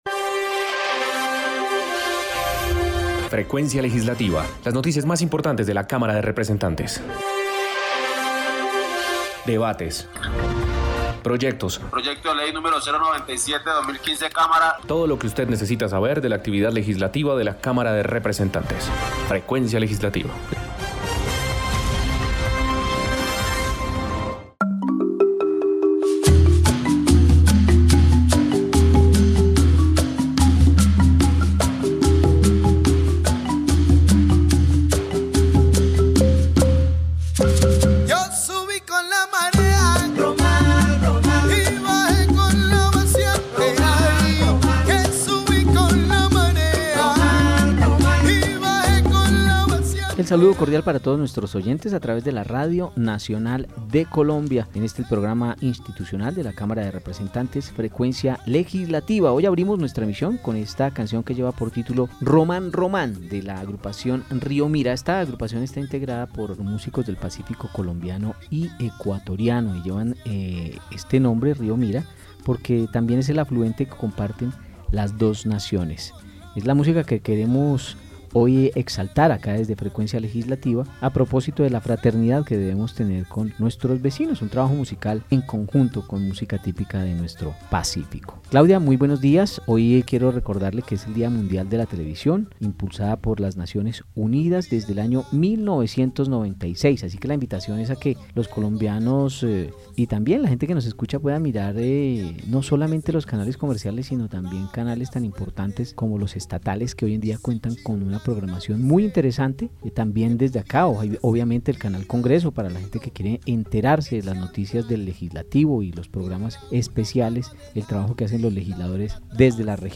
Programa Radial Frecuencia Legislativa. Sábado 21 de Noviembre de 2020